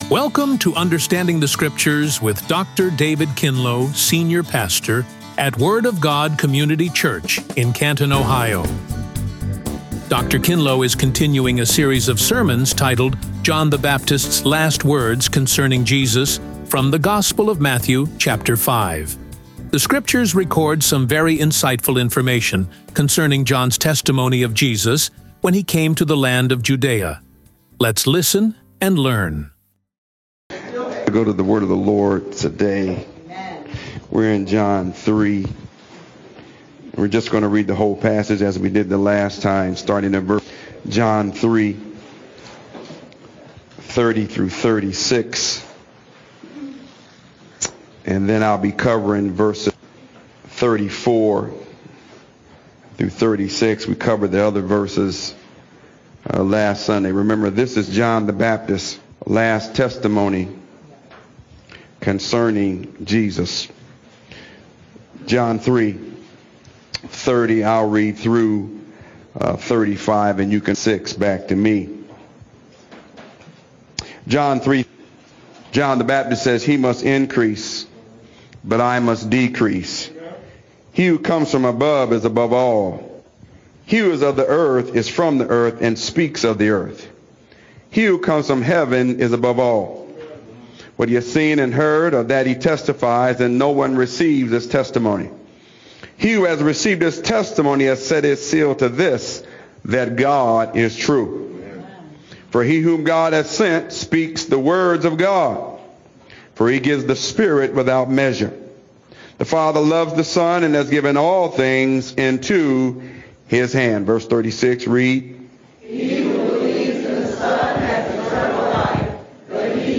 Radio Sermons | The Word of God Community Church